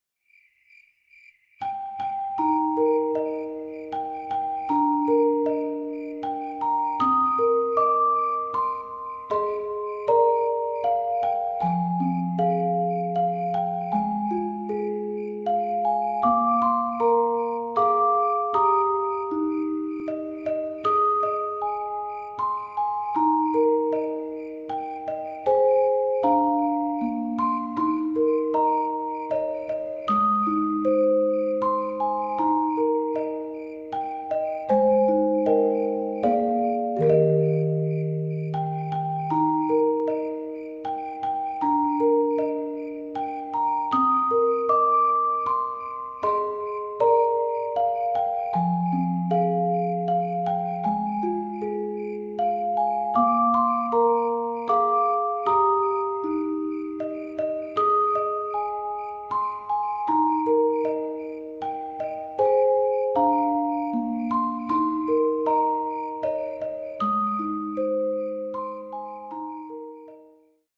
자장가(브람스) 1분10초.mp3